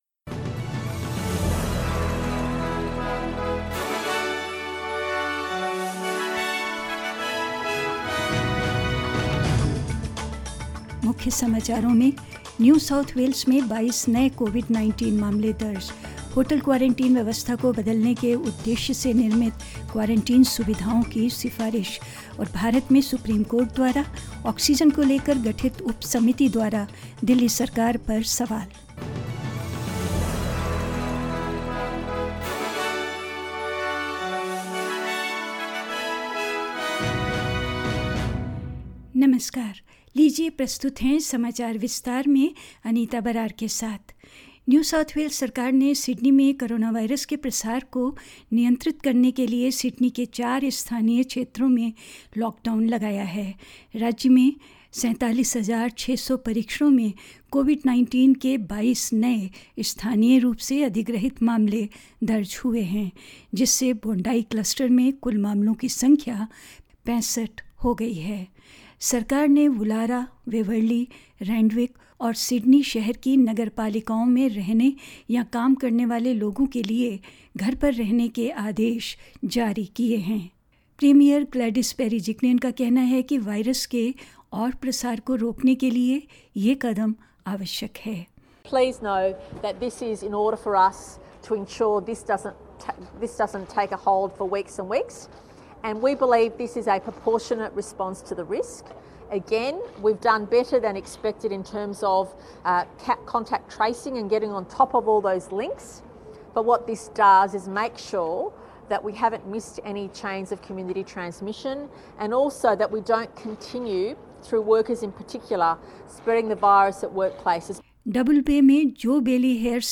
In this latest SBS Hindi News bulletin of Australia and India: New South Wales records 22 new COVID-19 cases as four Sydney areas are ordered into lockdown; Purpose-built quarantine facilities recommended to replace the hotel system in several major cities; Rejecting the comments by Pakistan Prime Minister Imran Khan on the nuclear deterrent, India reiterated its stand on bilateral ties with Pakistan; and more news.